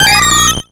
Cri de Zarbi dans Pokémon X et Y.